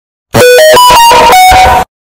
Description: loudest